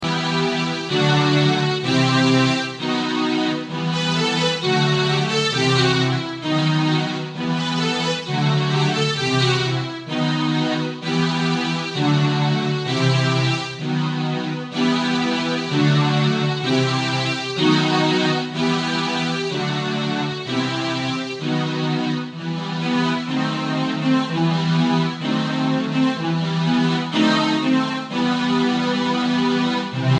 Listen to the instrumental backup track.